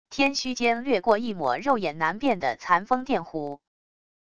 天虚间掠过一抹肉眼难辨的残风电弧wav音频